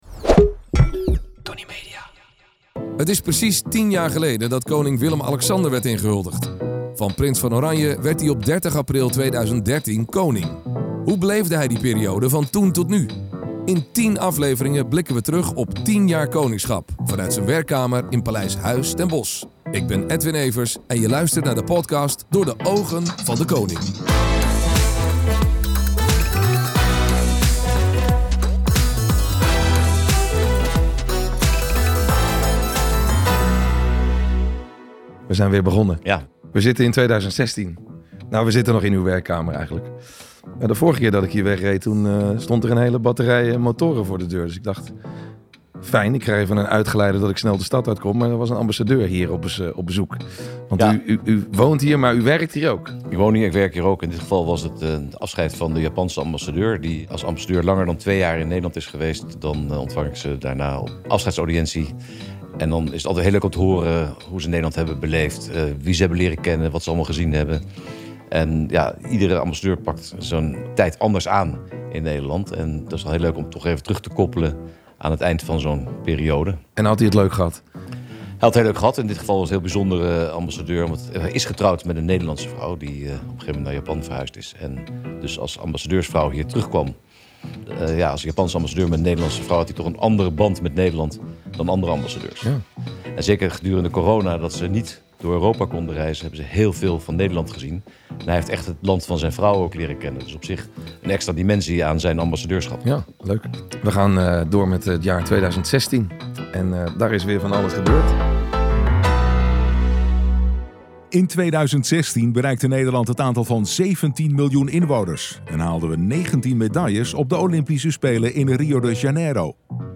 In tien afleveringen blikken we terug op tien jaar Koningschap, vanuit zijn werkkamer in Paleis Huis ten Bosch.